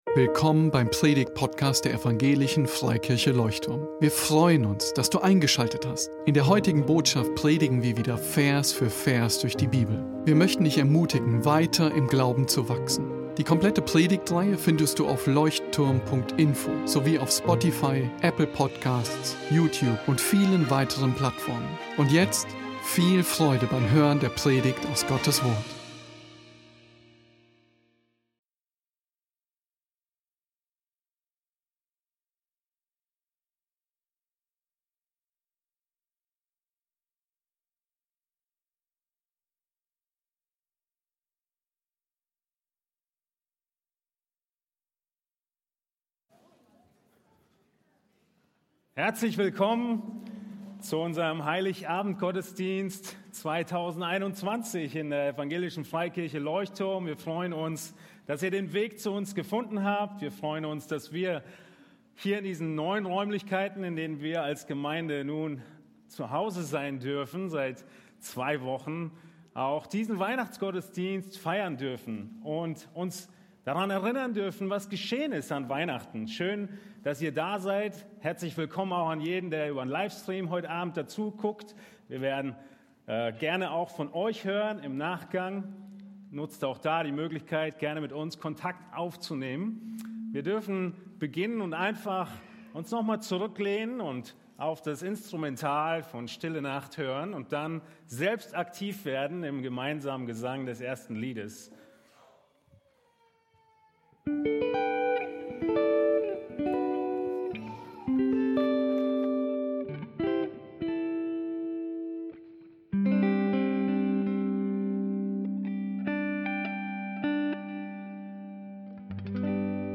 Heilig Abend Gottesdienst | Livestream ~ Leuchtturm Predigtpodcast Podcast